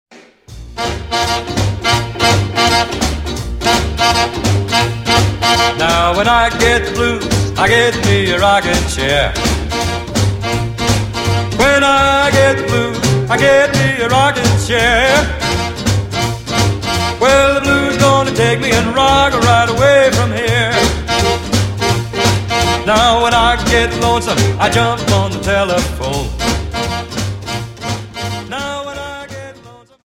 Dance: Jive 43,0